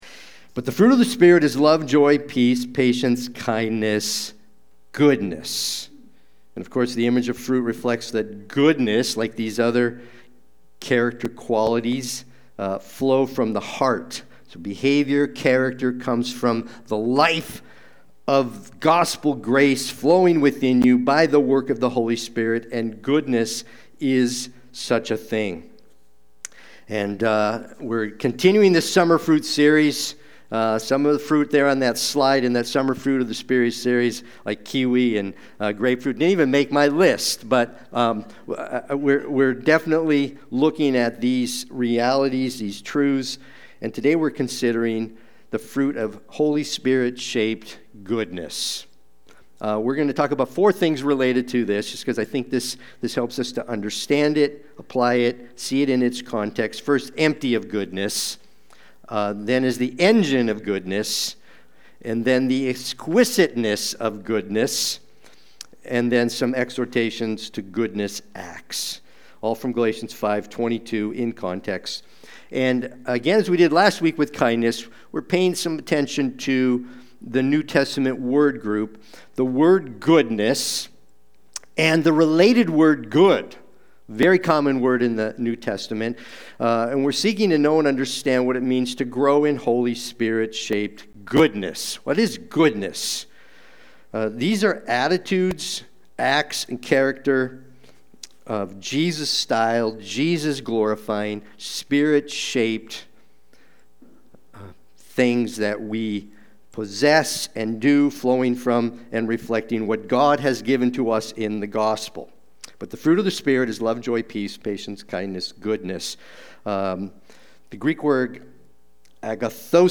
Sunday-Worship-main-72824.mp3